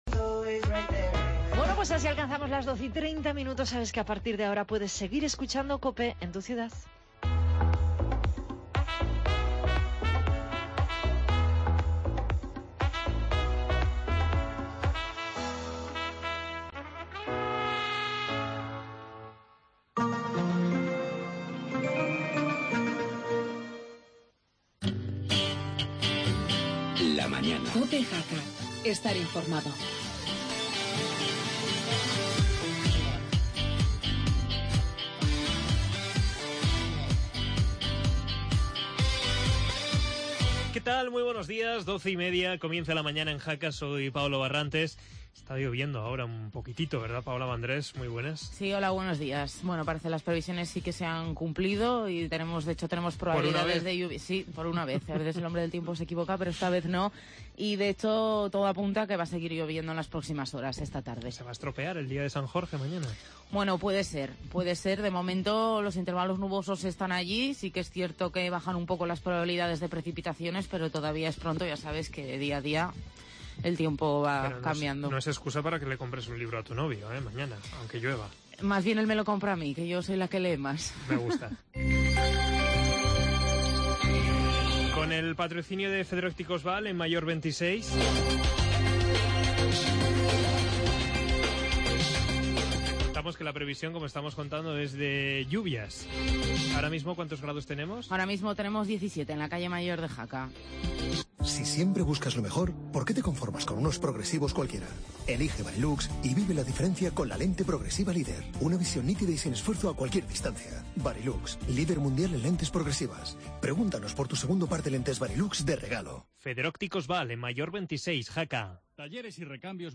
AUDIO: Actualidad de Jaca y Sabiñánigo, entrevista a Víctor Barrio que presenta la lista del PP y presentación de la I Carrera por...